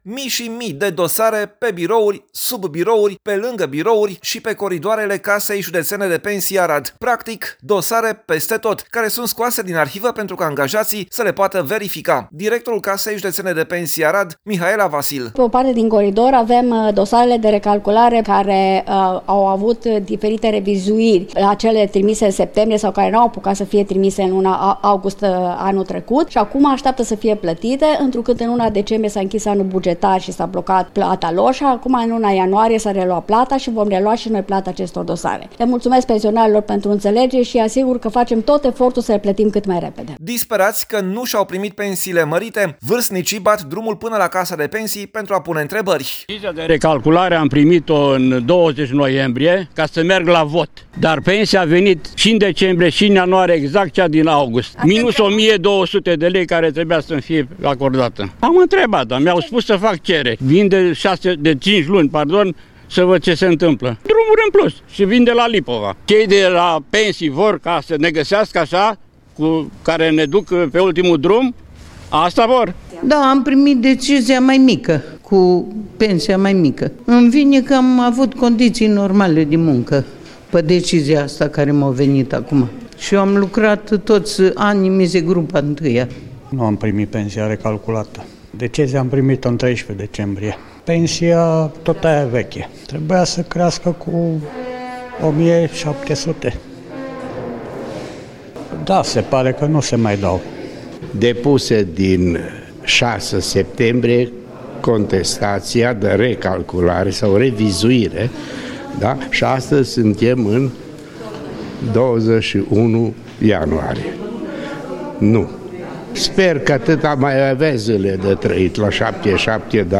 Pensionar din Arad: „Vin de 5 luni să văd ce se întâmplă”